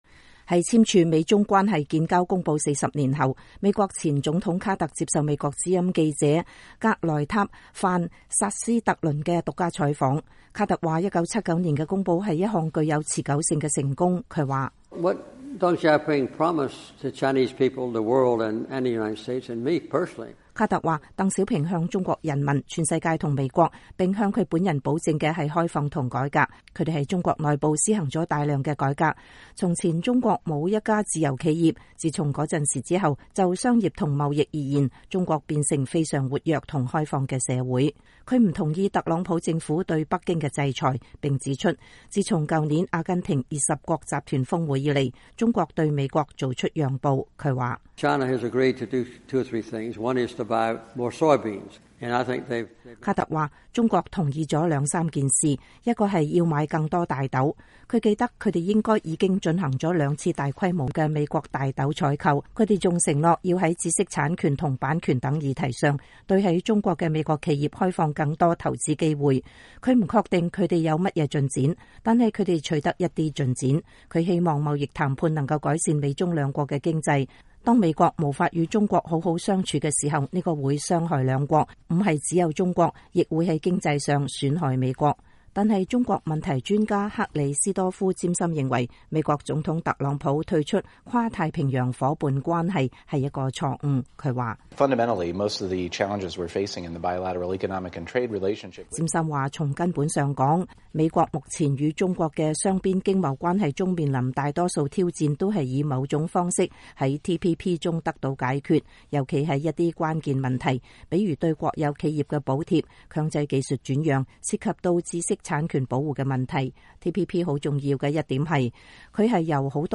在簽署《美中關建交公報》40年後，美國前總統卡特接受了美國之音記者格萊塔·範·薩斯特倫的獨家採訪。